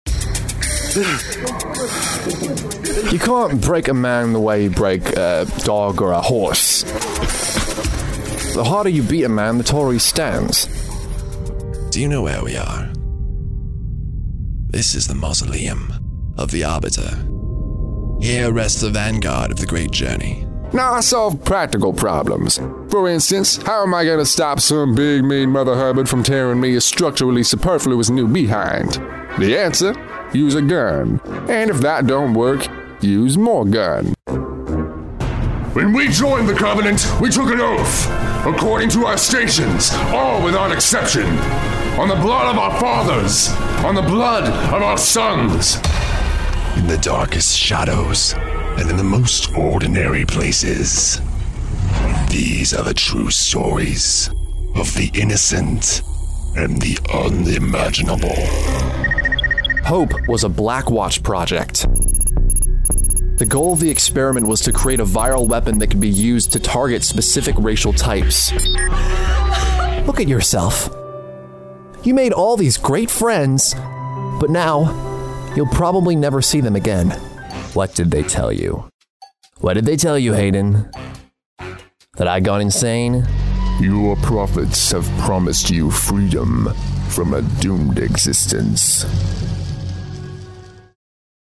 Male
Genuine youthful sound, but with range in more mature sounds as well.
Broad range of voices for character purposes, with inhuman and creature sounds.
Video Games
Broad Age Range / Inhuman Sound
Words that describe my voice are Youthful, Friendly, Conversational.